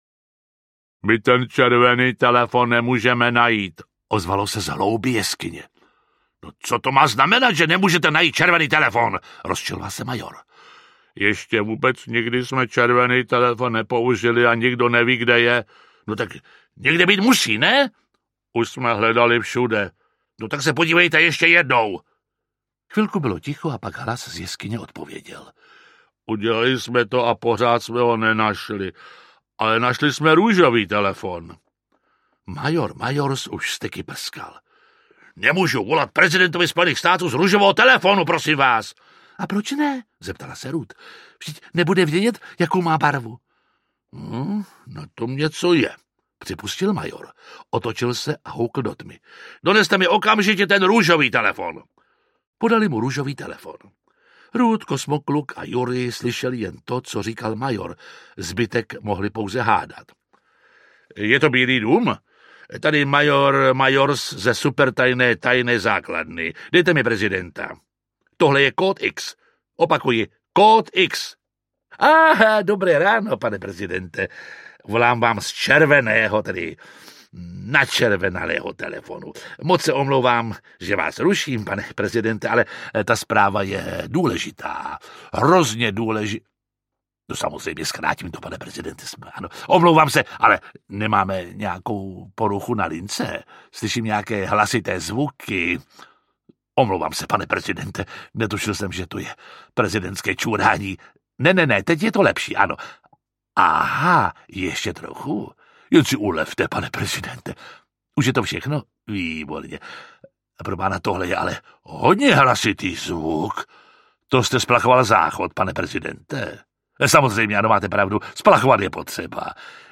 Kosmokluk audiokniha
Ukázka z knihy
Čte Jiří Lábus.
Vyrobilo studio Soundguru.